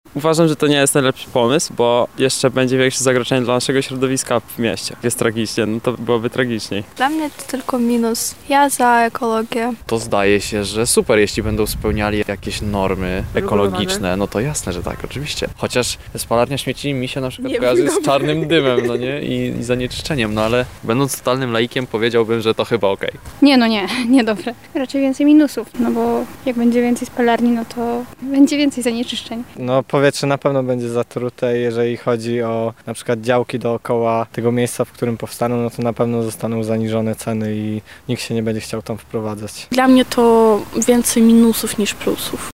SONDA: Co myślą o spalarniach śmieci Lublinianie
Zapytaliśmy mieszkańców Lublina, co sądzą na ten temat:
Sonda